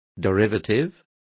Complete with pronunciation of the translation of derivative.